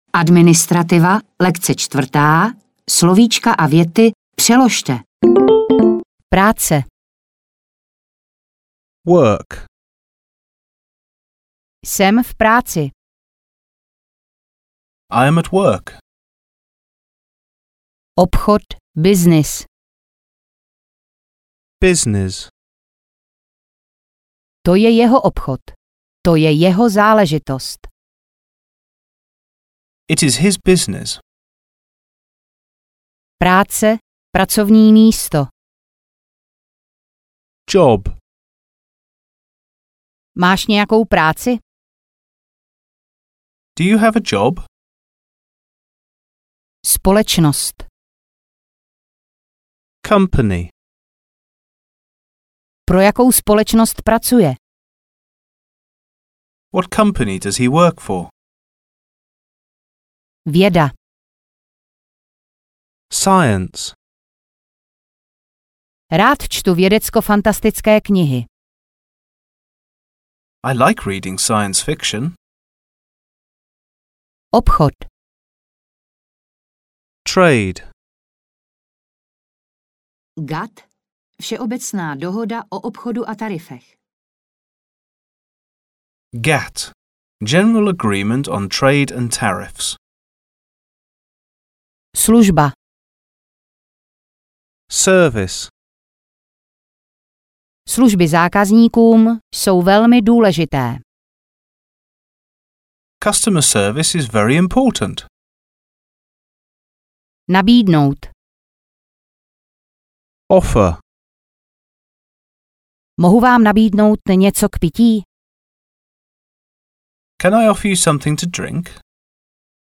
Obchodní angličtina pro začátečníky audiokniha
Ukázka z knihy